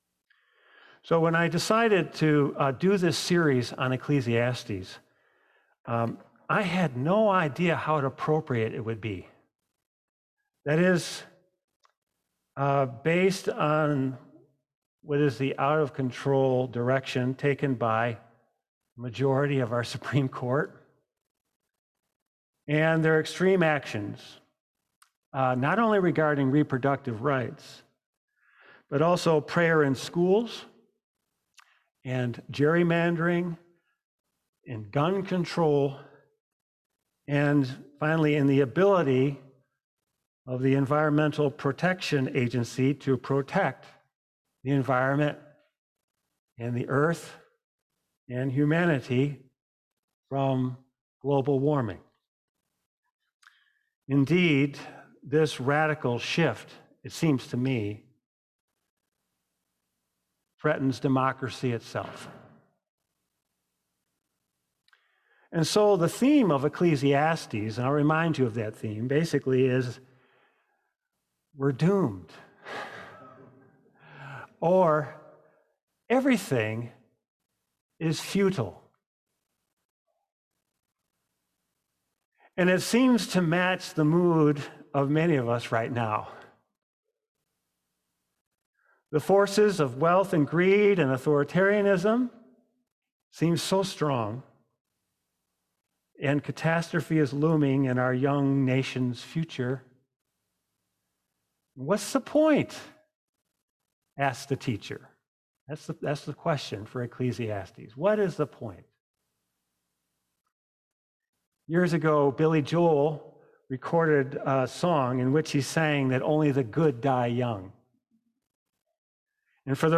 7-3-22-sermon.mp3